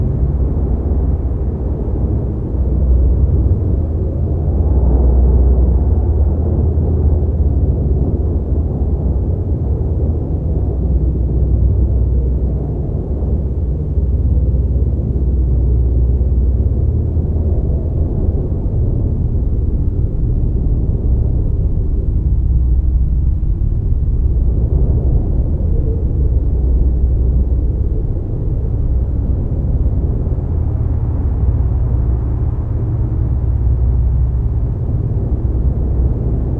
hungryBeast.ogg